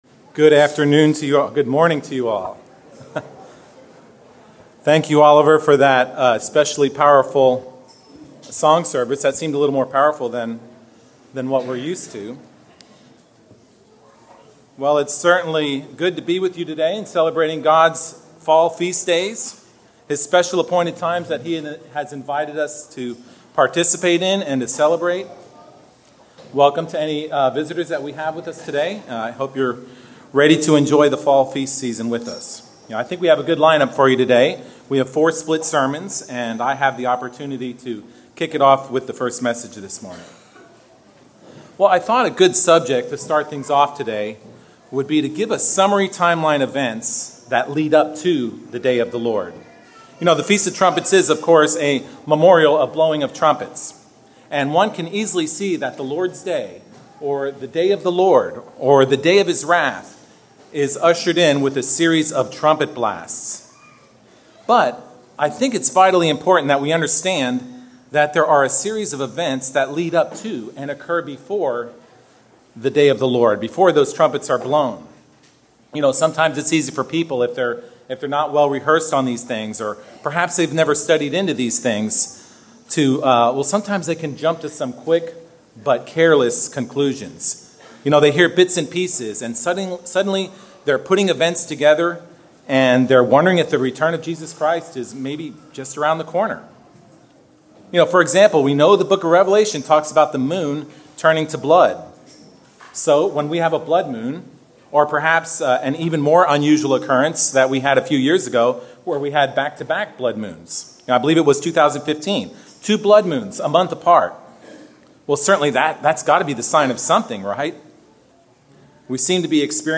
As the first of four split sermons on the Feast of Trumpets, we will begin the day by examining the series of events leading up to, but NOT including, the loosing of the seventh seal and the blowing of seven trumpets. We will examine Rev.6 and the loosing of six seals and comparing it to the plain speaking explanation of Jesus Christ in the Gospels; primarily the account as told by Matthew (ch.24) but with some added details from Luke (ch.21).
Given in Dallas, TX